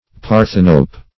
Search Result for " parthenope" : The Collaborative International Dictionary of English v.0.48: Parthenope \Par*then"o*pe\ (p[aum]r*th[e^]n"[-o]*p[=e]), n. [L., the name of a Siren, fr. Gr.